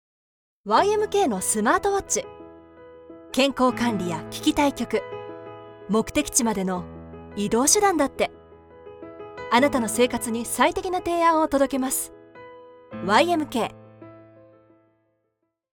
Commercial, Deep, Reliable, Friendly, Corporate
Commercial
Her voice is versatile, stylish, luxurious, authentic, yet believable